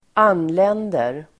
Uttal: [²'an:len:der]